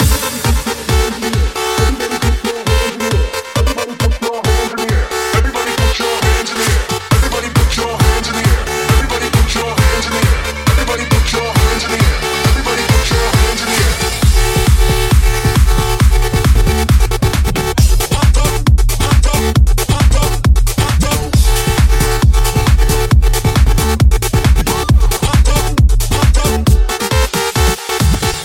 Genere: house,deep,edm,remix,hit